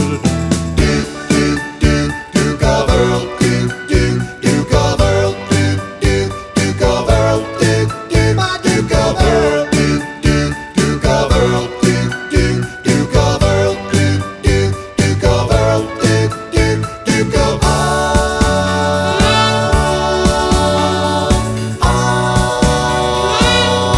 No Intro Ooohs Rock 'n' Roll 3:45 Buy £1.50